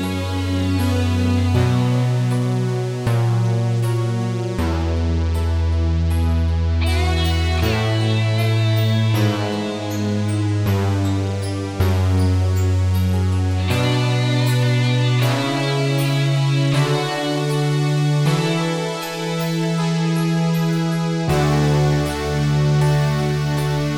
Minus Piano Pop (1980s) 4:44 Buy £1.50